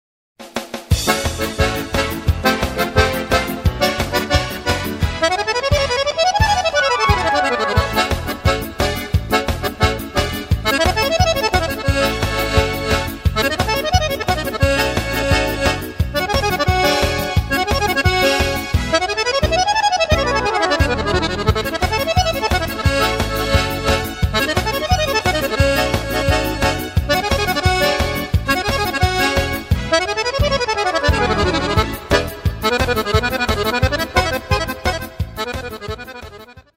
Playback + Akkordeon noten